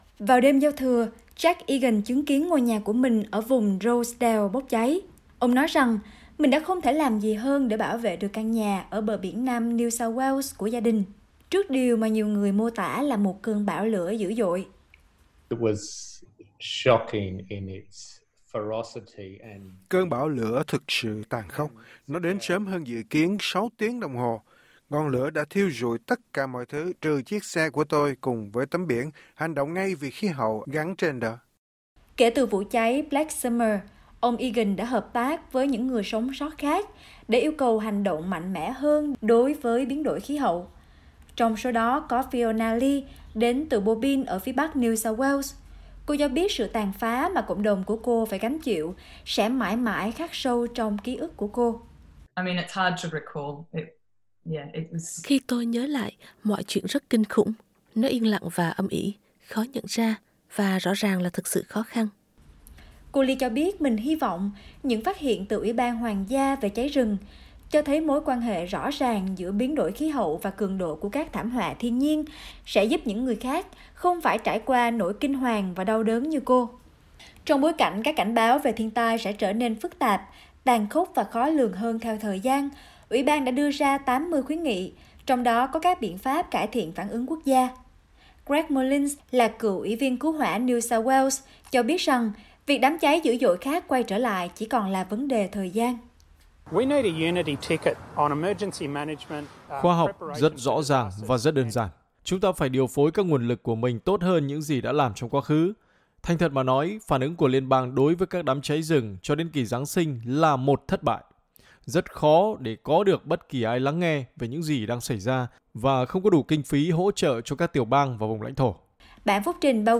vietnamese_bushfire_report_rtb.mp3